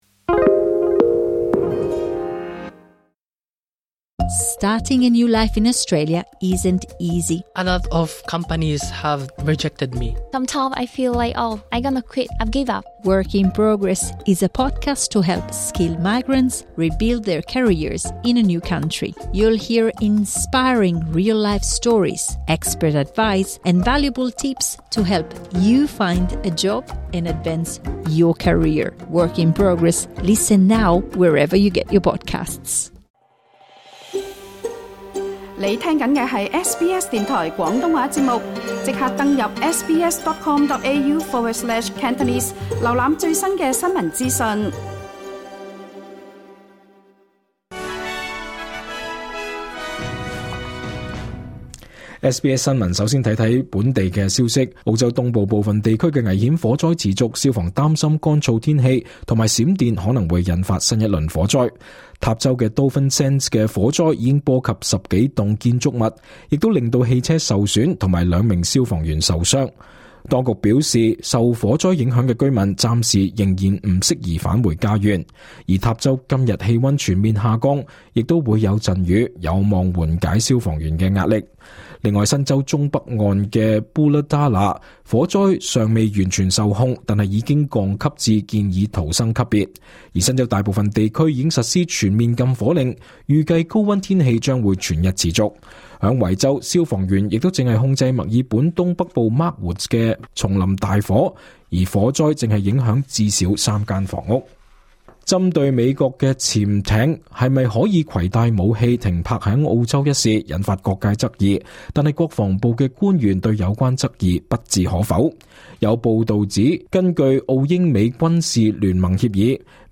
2025 年 12 月 6 日 SBS 廣東話節目詳盡早晨新聞報道。